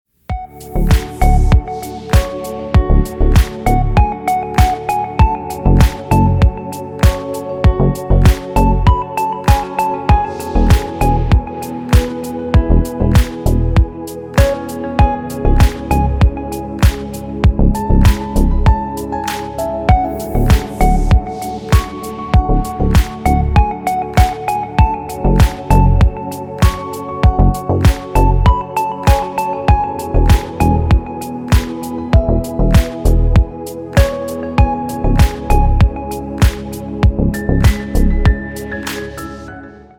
Спокойные рингтоны